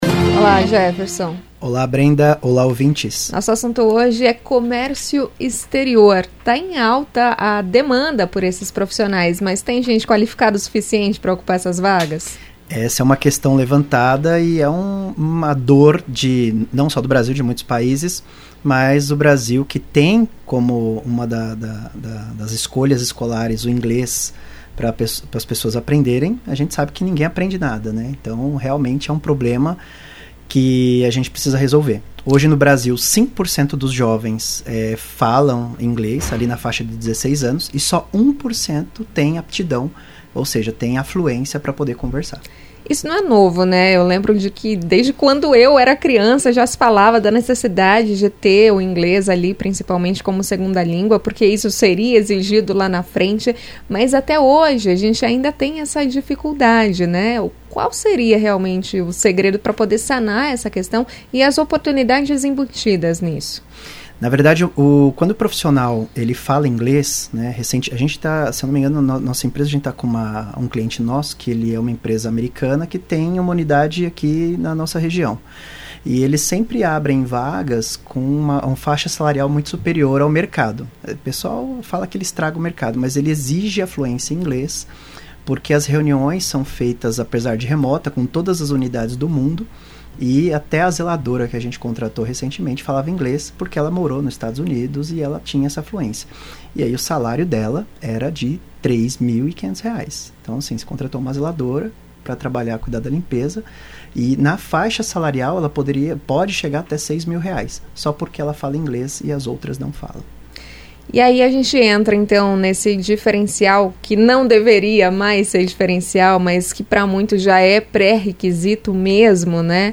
Acompanhe CBN RH, às quartas-feiras, às 11h15, dentro do CBN Maringá 1ª. Edição